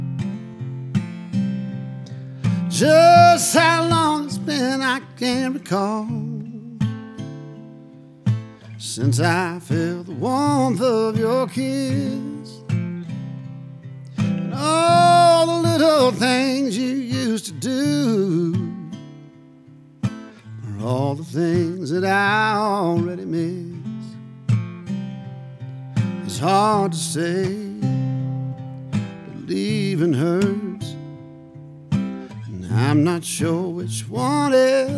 Country
Жанр: Кантри